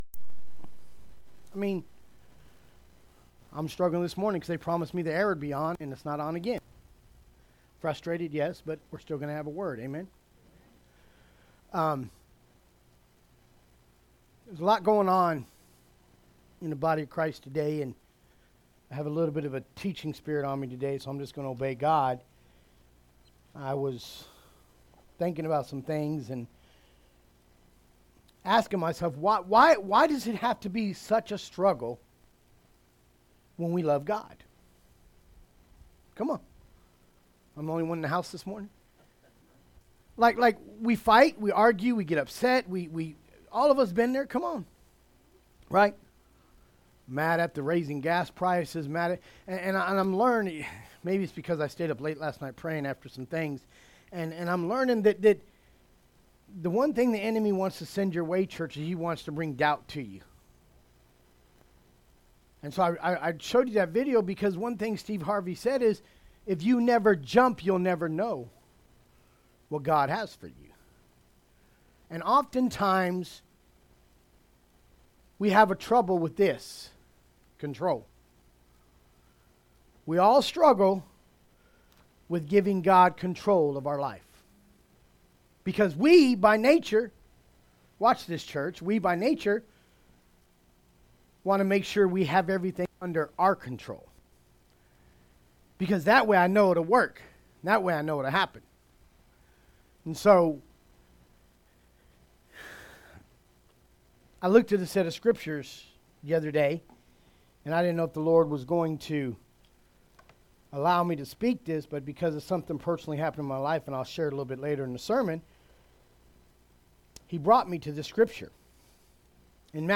Passage: Matthew 14:13 - 20 Service Type: Sunday Service « Living By The Spirit